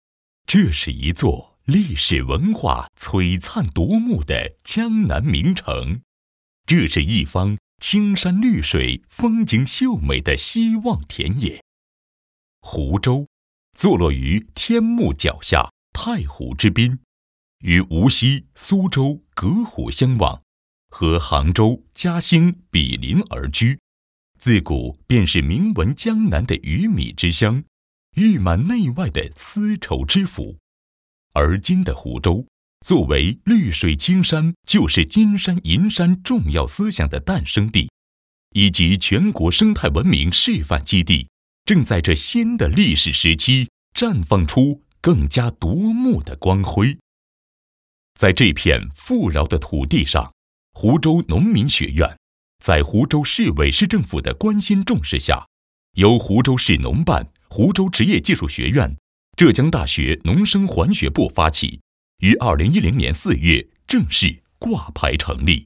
毕业于中国传媒大学播音主持专业，从事配音行业数年，普通话一级甲等水平，全能型风格加之高端的品质，让他的声音得到观众和业内的认可。